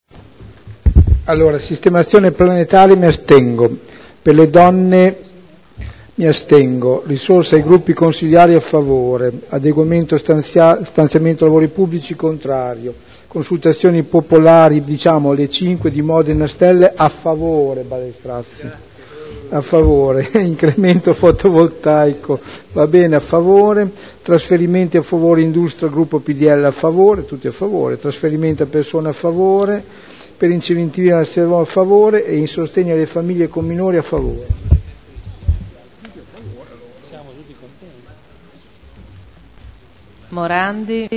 Dichiarazioni di voto su emendamenti.